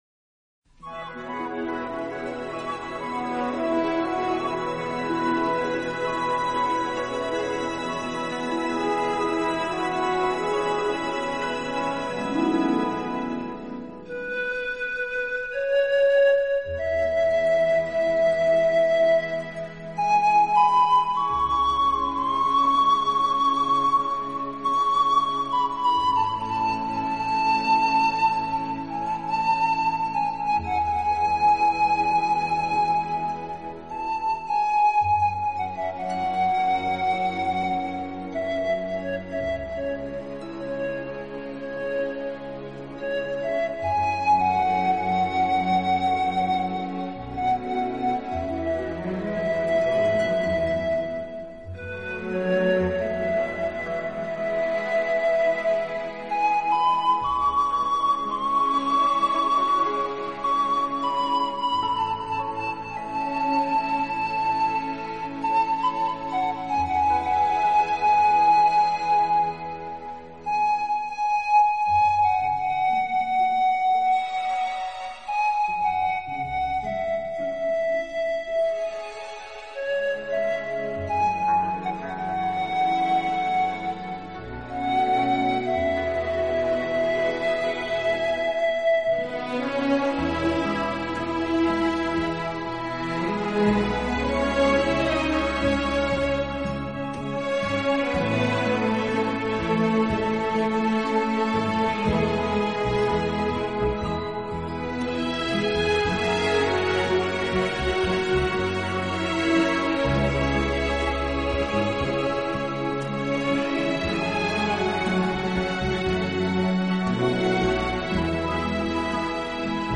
宁静的午夜，音箱里轻轻地漂浮出一串静谧的音乐，如诗歌般的悠扬，似梦幻般的恬静